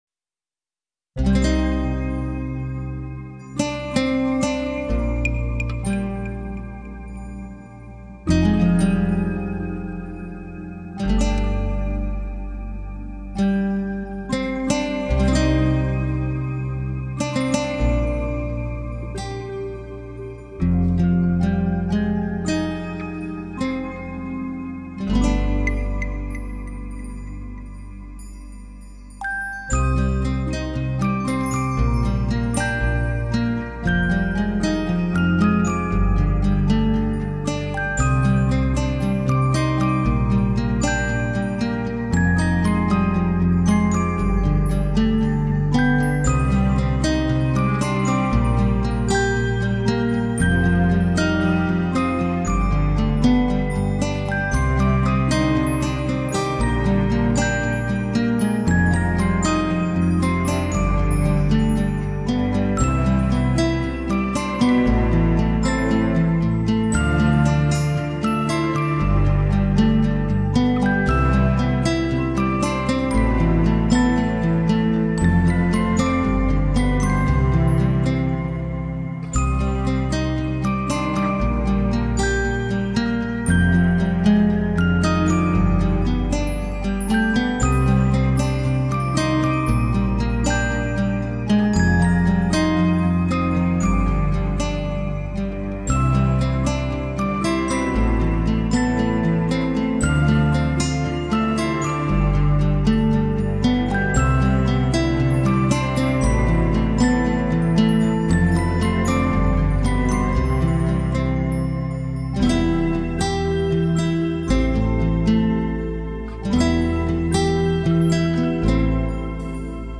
这首曲子，是我80年代的收藏了，是从小贩那里买的，是走私的打了口的碟子，曲子录音相当漂亮